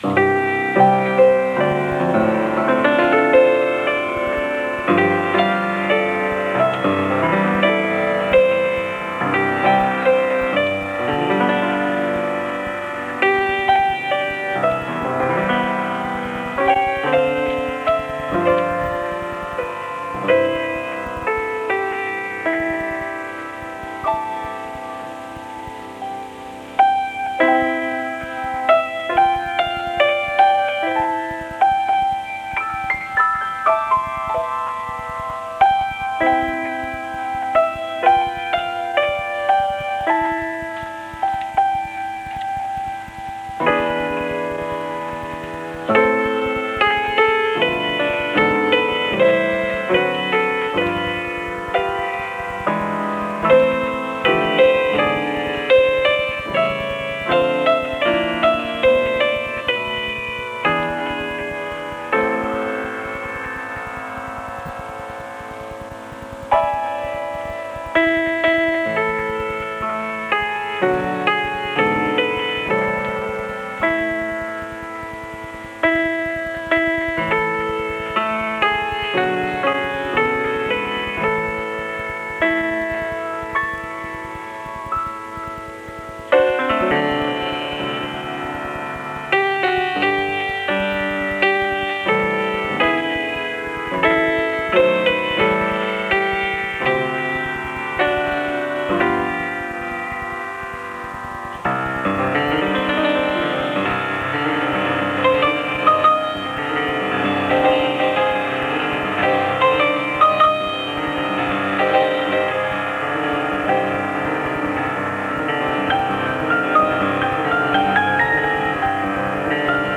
Enregistrat al piano de l'estudi 1 de RNE a la sisena planta del Passeig de Gràcia 1 de Barcelona, el desembre de 1987.